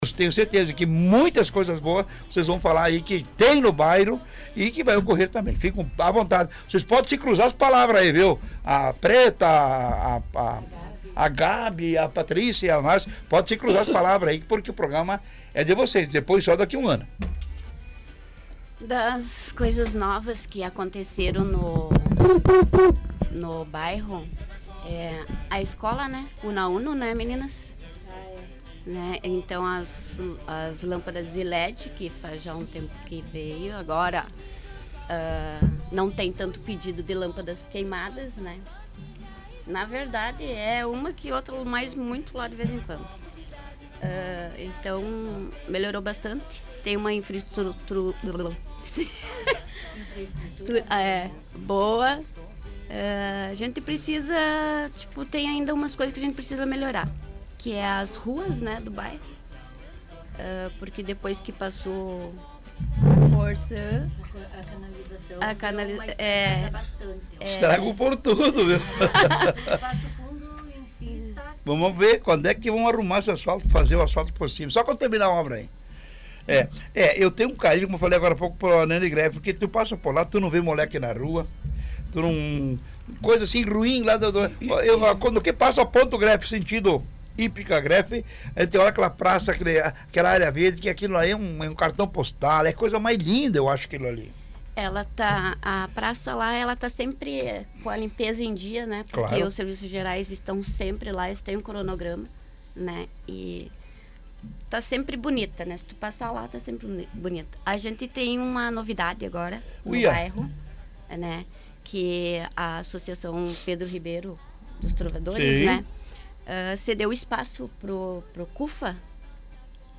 A diretoria da Associação de Moradores esteve presente no estúdio da Rádio Planalto News (921.) no sábado, 20.